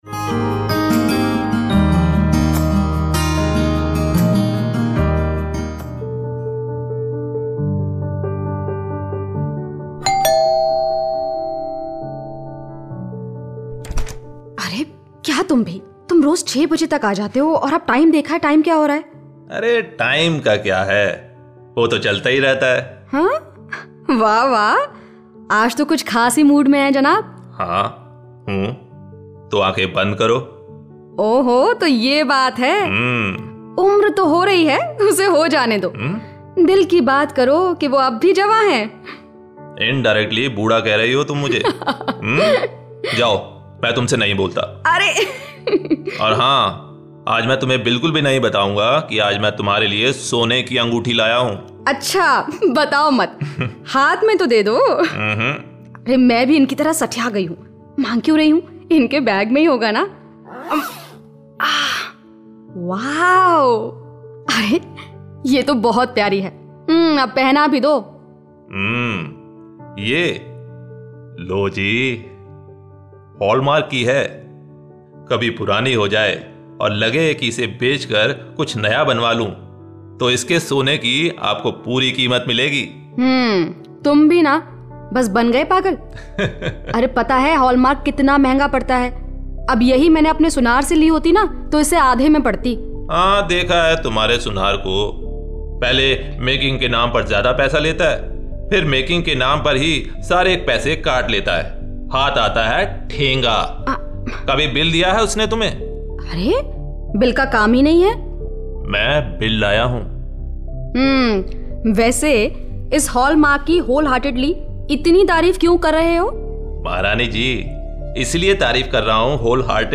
Skit on promotion of Hallmark.